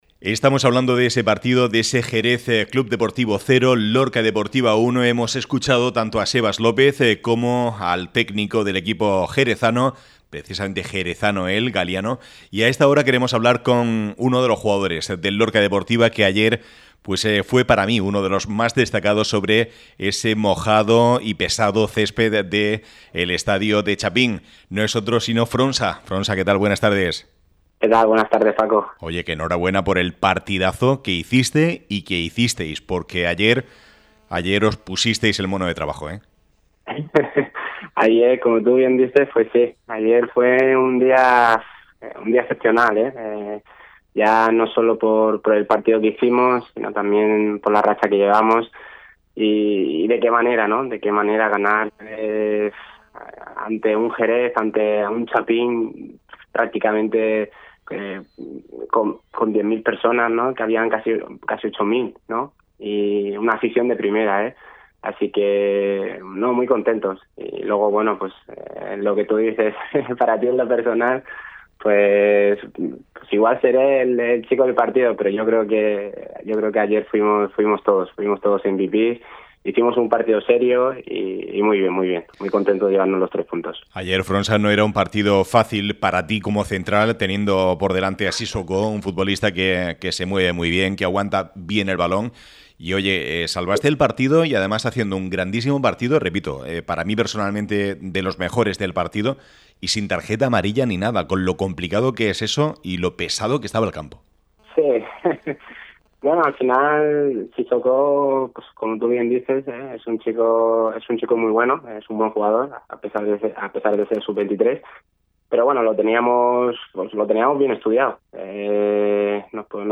ÁREA LORCA RADIO. Deportes.